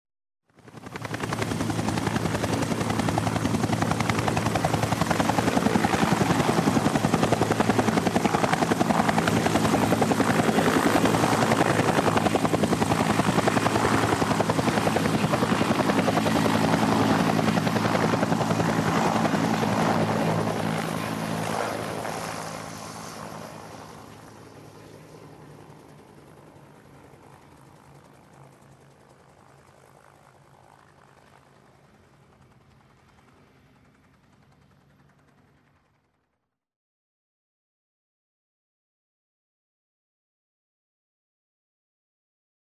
SFX战争色彩浓烈的飞机素材音效下载